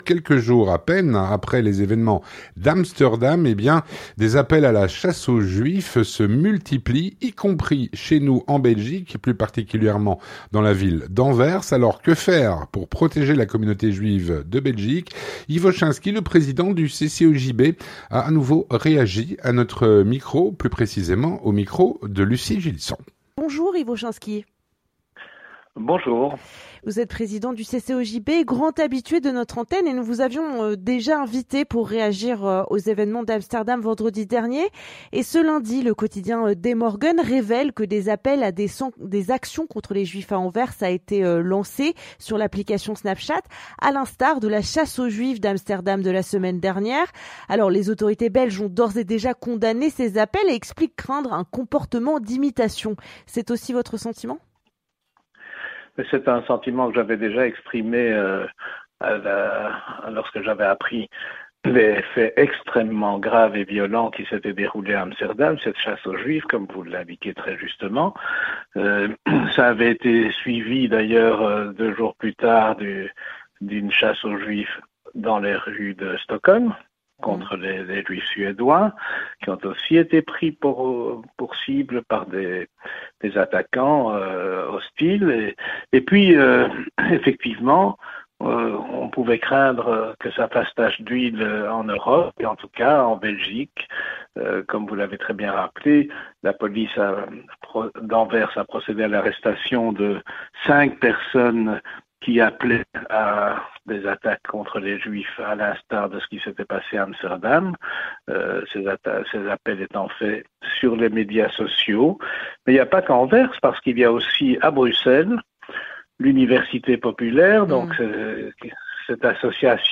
L'entretien du 18H - Appels à “la chasse aux juifs” en Belgique.